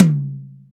TOM RLTOM0RR.wav